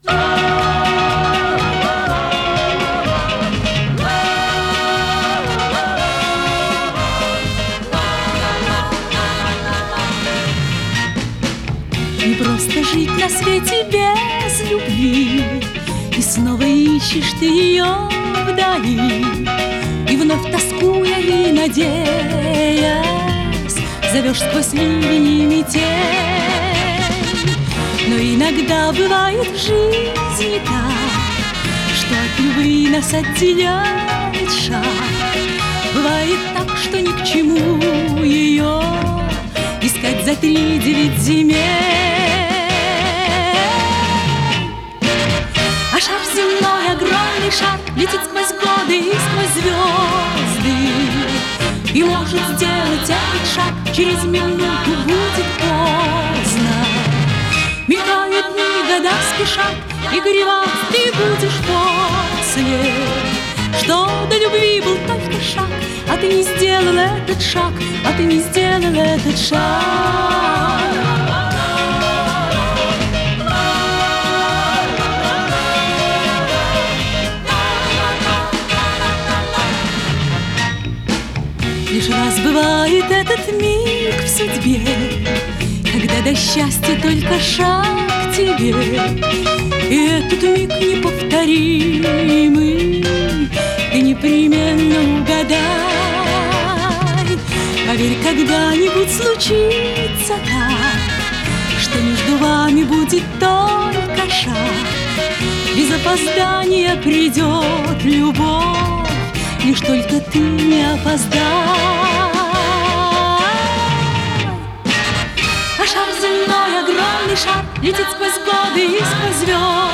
пение
ВариантДубль моно